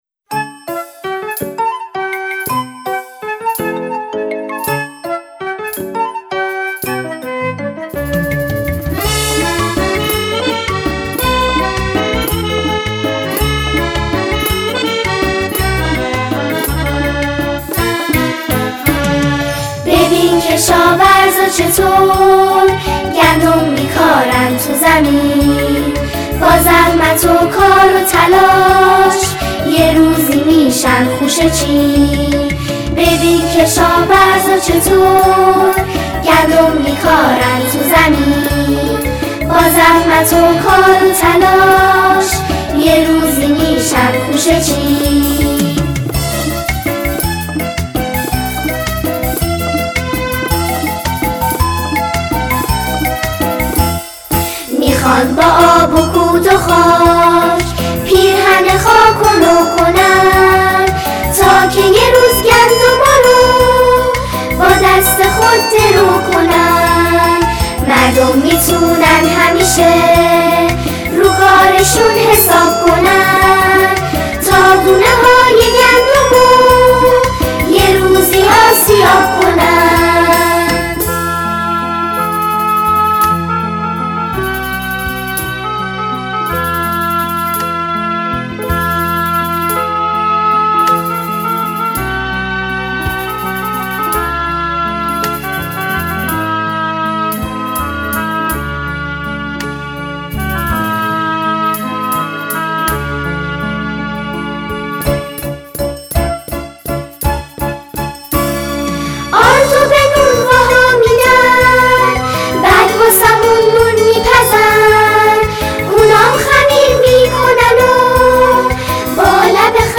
نماهنگ سرود «از سفره تا مردم» اثر جدید کانون پرورش فکری کودکان و نوجوانان استان خوزستان به سفارش اداره کل غله و خدمات بازرگانی استان خوزستان و به مناسبت دومین جشنواره ملی نان خوزستان تولید شد. هدف از ساخت این اثر، معرفی و تقدیر از فعالان زنجیره تولید نان و همچنین ترویج فرهنگ پرهیز از اسراف نان در جامعه عنوان شده است.
اجرای گروه سرود "خورشیدنهان"
در محل نمایشگاه دومین جشنواره ملی نان واقع در سیلوی تاریخی اهواز ضبط شد.